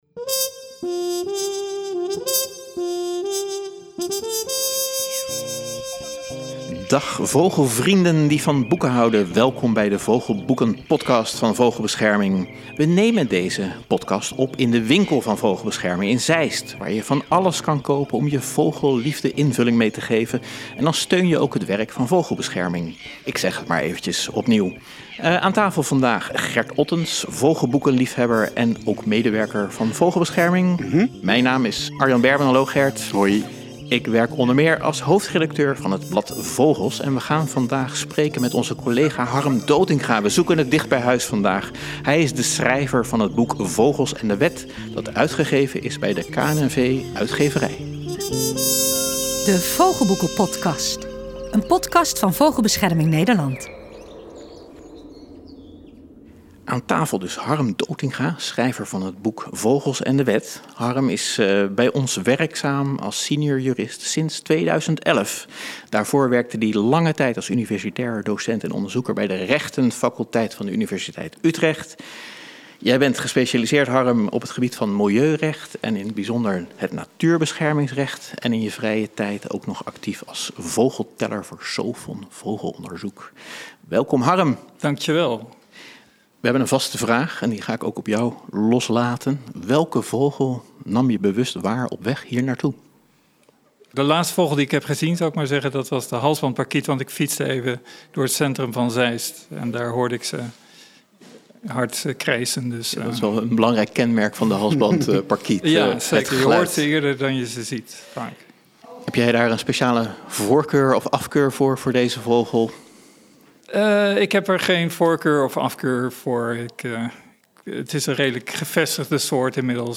Redactie en interviews